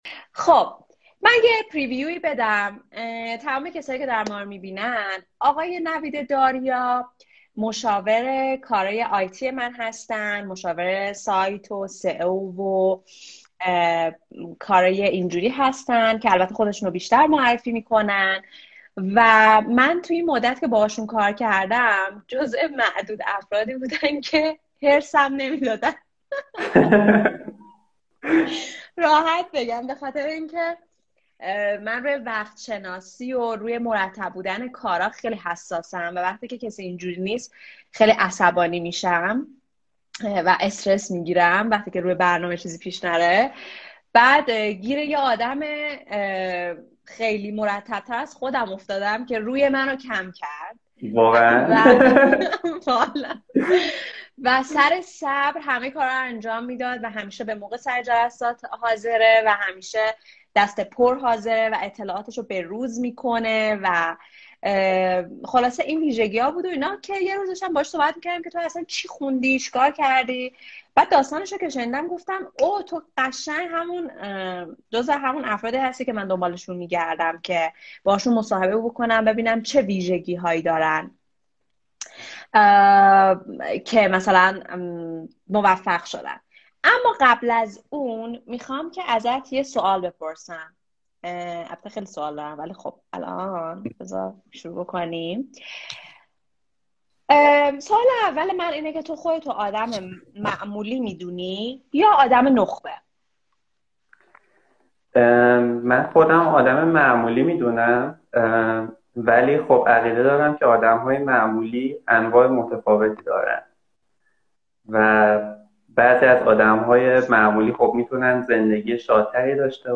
لایو